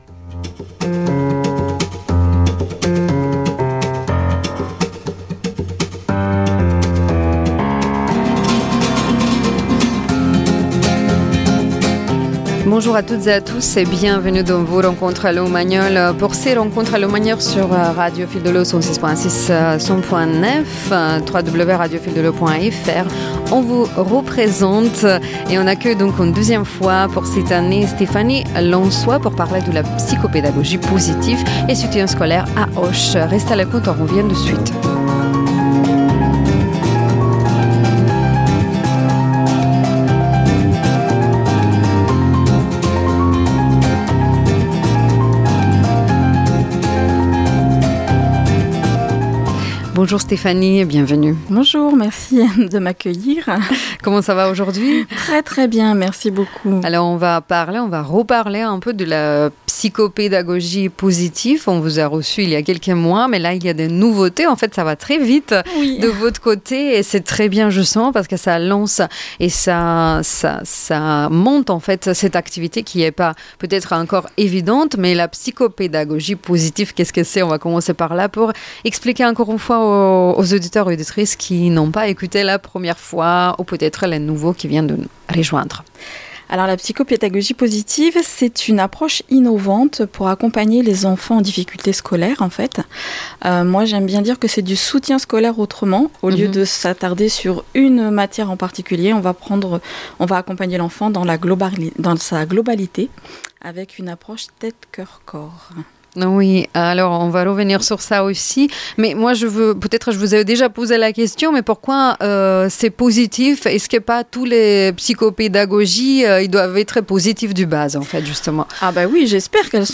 Récemment, j’ai eu le plaisir d’être invitée sur Radio Fil de l’Eau pour parler de mon approche en psychopédagogie positive et de l’accompagnement des jeunes face aux défis scolaires.
Lors de cette interview, nous avons exploré comment l’approche tête–cœur–corps peut aider les enfants et adolescents à retrouver confiance, motivation et sérénité dans leurs apprentissages.
Nous avons également expérimenté une courte méditation en direct, un moment doux et ressourçant que je vous invite à découvrir.